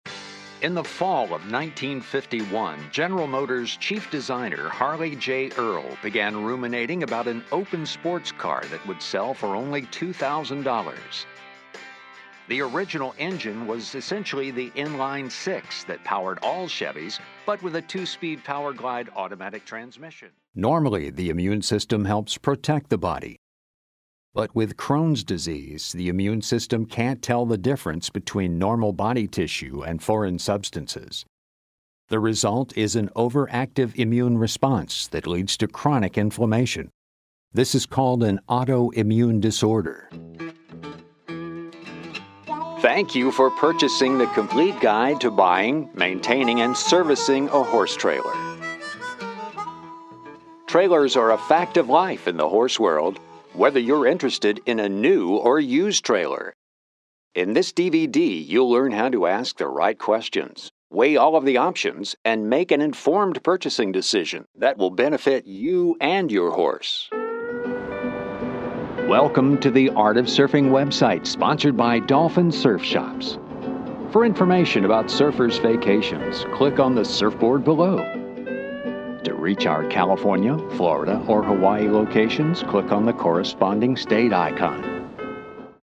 He has a very good range, from low to high.
englisch (us)
Sprechprobe: Industrie (Muttersprache):